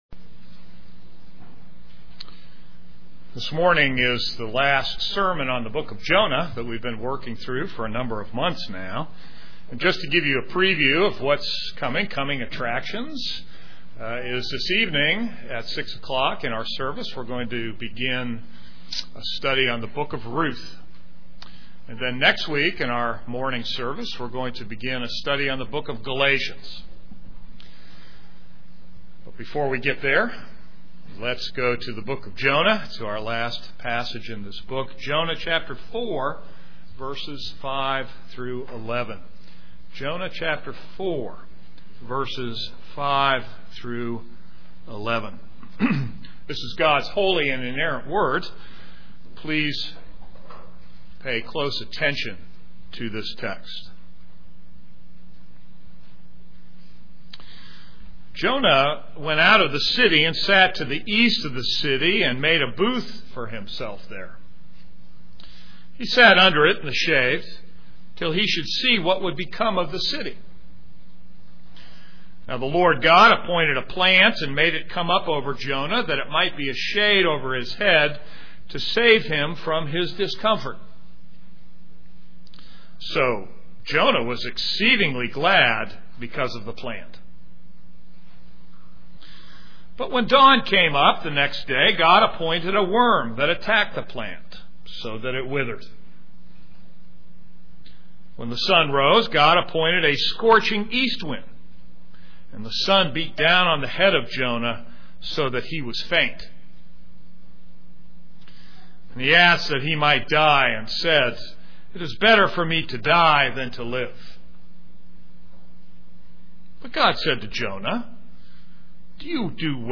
This is a sermon on Jonah 4:5-11.